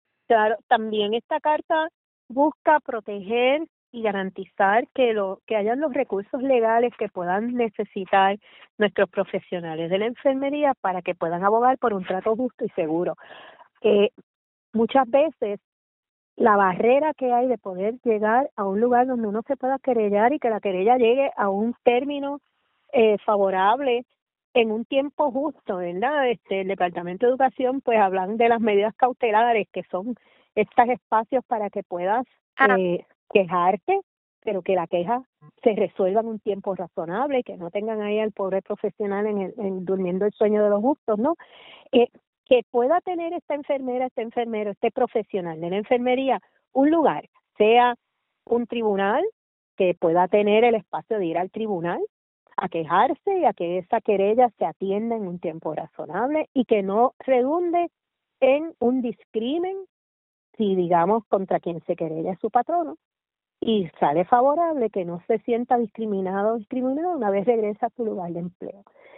La legisladora explicó en entrevista con Radio Isla que busca que “se pueda garantizar que como profesionales tengan unas condiciones de trabajo dignas, que tengan un espacio de bienestar y que la calidad de vida de los profesionales de la enfermería también se garanticen“, y describió a estos profesionales como la piedra angular en el sistema de salud, dado a que son quienes constatan de cerca la condición de los pacientes.